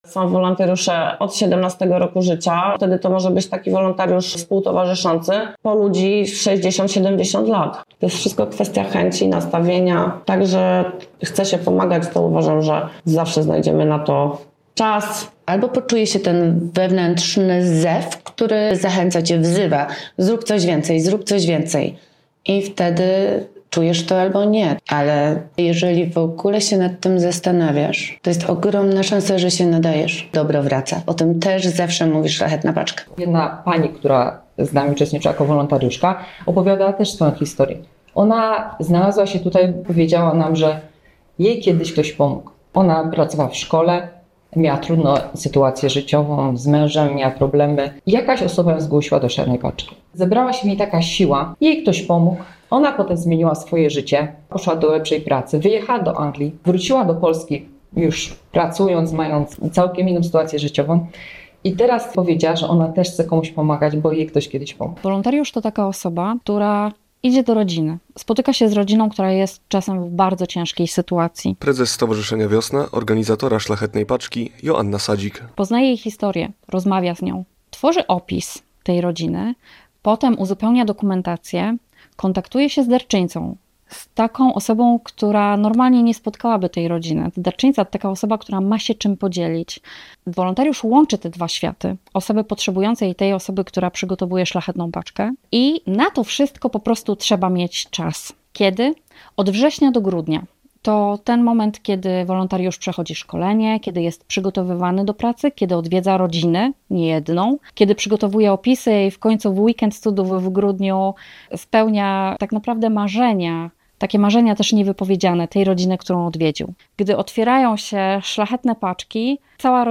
Dlaczego warto wesprzeć tę akcję i zaangażować się w pomóc potrzebującym - opowiadają sami wolontariusze.